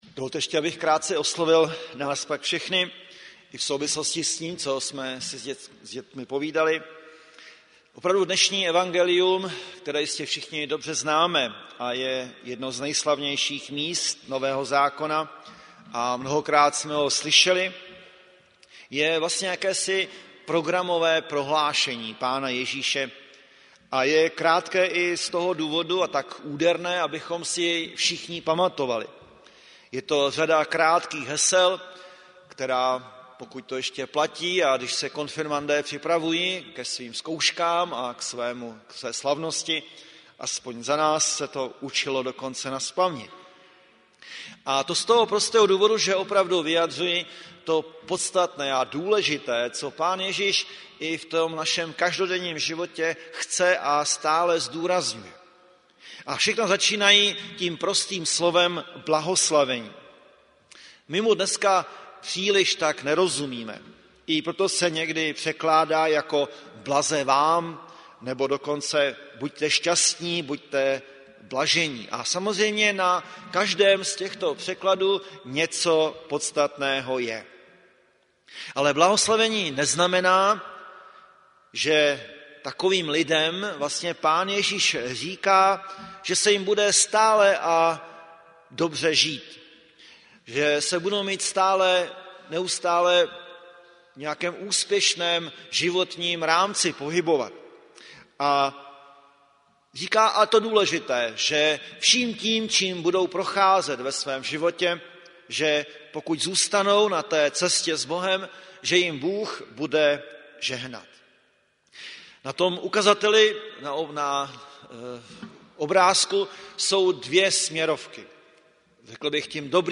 Záznam kázání a sbrová ohlášení z bohoslužeb - rodinné neděle konaných dne 18.2.2018 v evangelickém chrámu Mistra Jana Husi v Plzni.